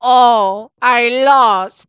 11_luigi_game_over.aiff